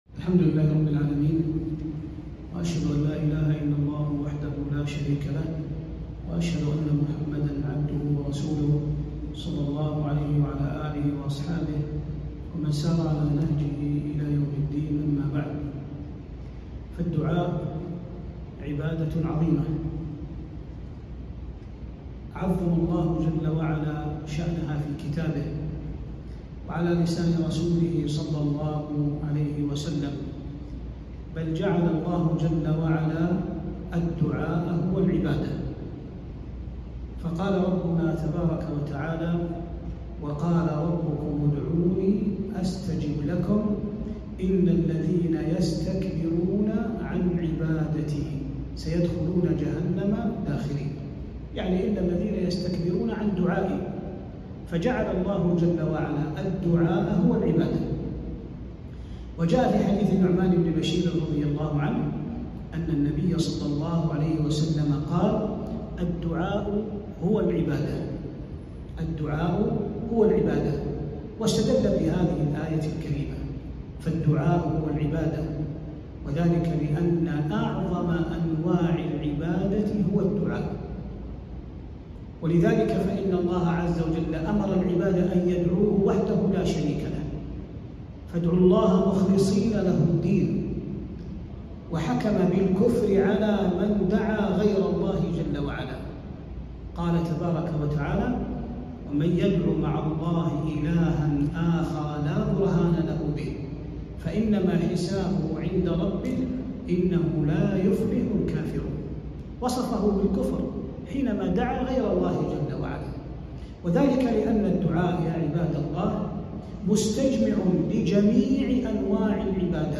كلمة - أنواع الدعاء